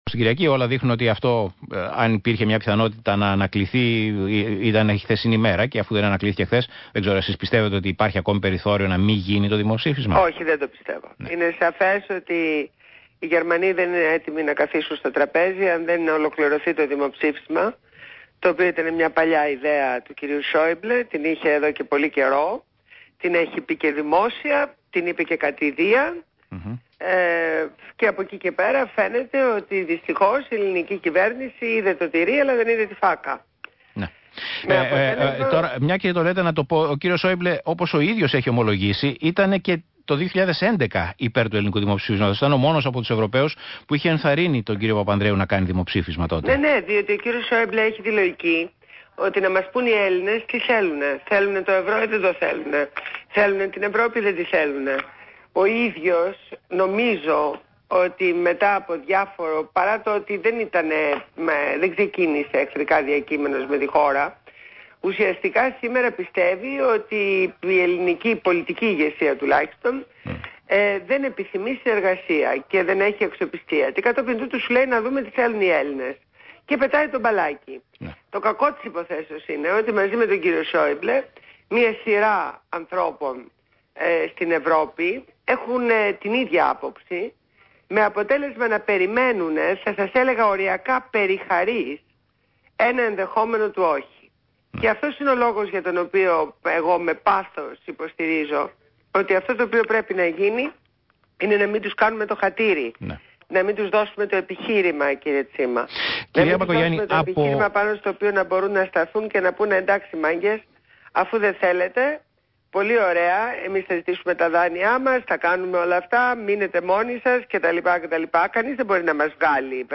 Συνέντευξη στο ραδιόφωνο του ΣΚΑΙ